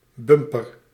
Ääntäminen
Synonyymit stootkussen buffer Ääntäminen Tuntematon aksentti: IPA: /ˈbʏmpər/ Haettu sana löytyi näillä lähdekielillä: hollanti Käännös Konteksti Ääninäyte Substantiivit 1. bumper auto US Suku: m .